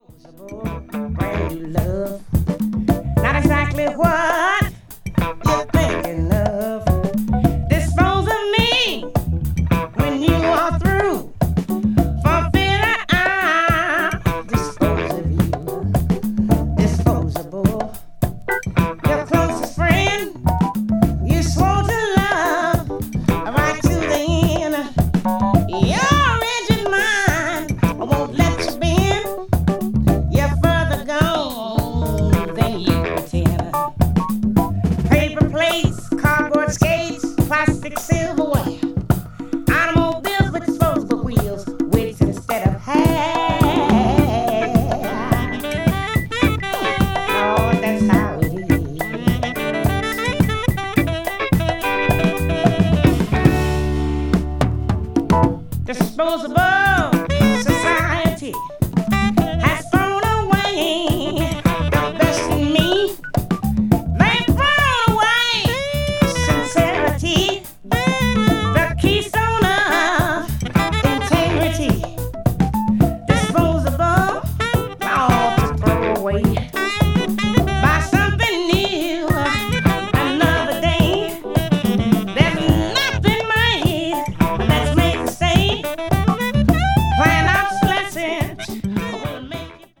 とにかく素晴らしい音質。
blues jazz   jazz funk   jazz vocal   soul jazz